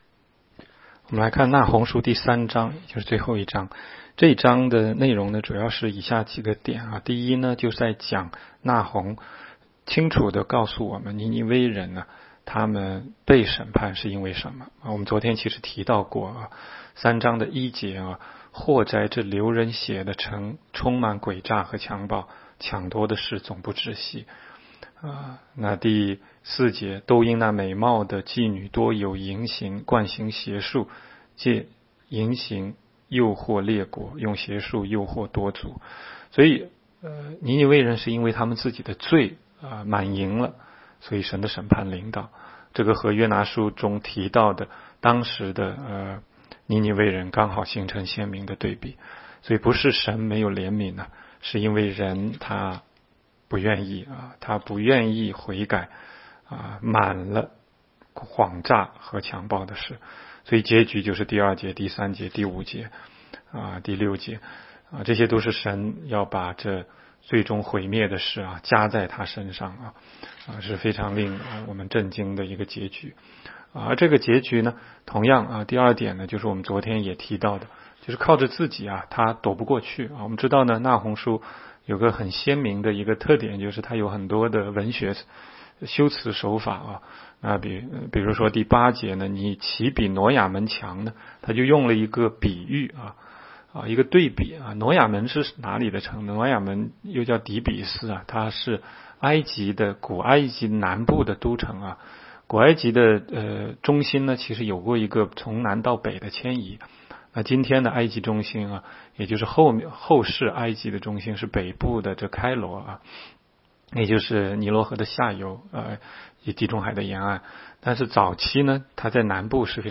16街讲道录音 - 每日读经 -《那鸿书》3章